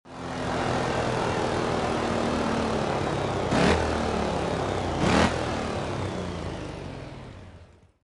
throttle_off.mp3